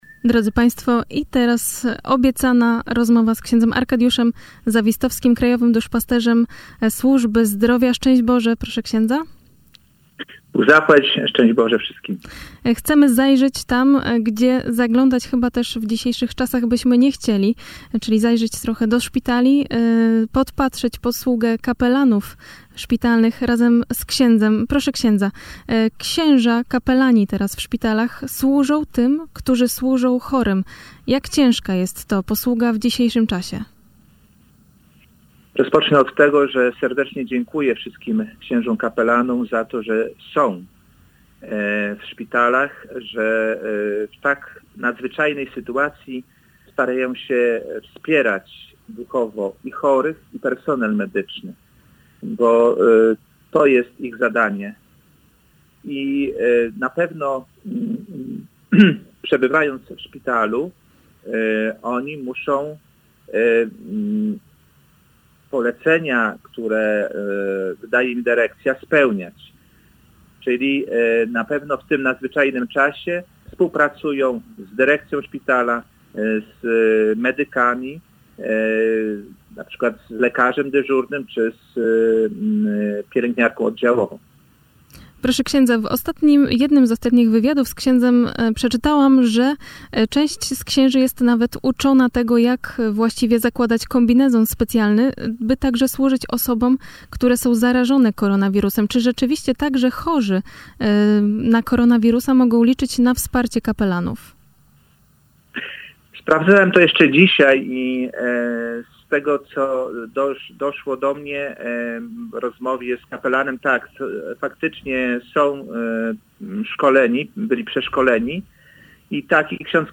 Cała rozmowa
Rozmowa31.mp3